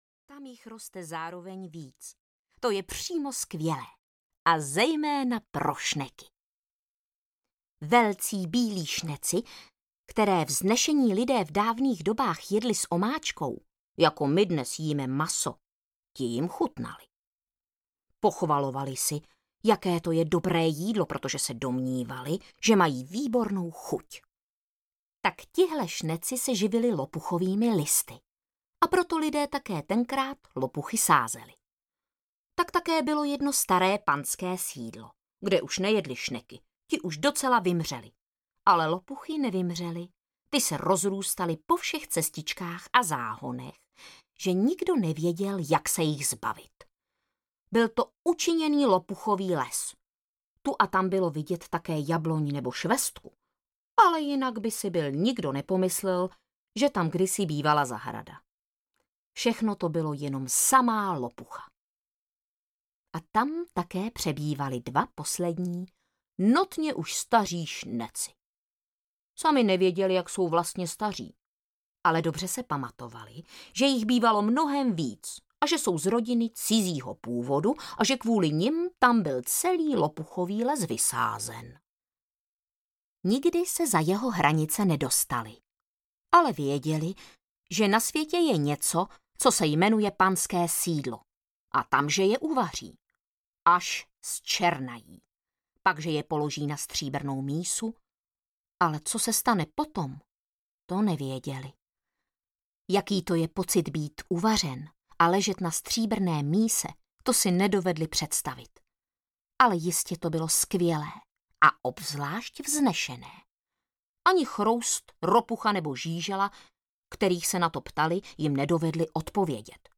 Šťastná rodina audiokniha
Ukázka z knihy
• InterpretVáclav Knop